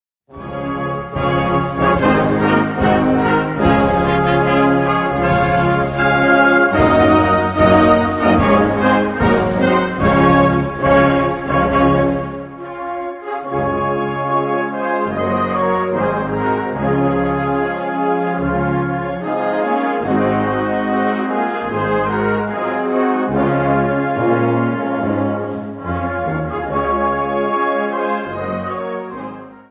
Gattung: Frohnleichnamslied
Besetzung: Blasorchester
Parade- und Prozessionsmarsch